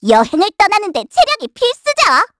Estelle-Vox_Skill4_kr.wav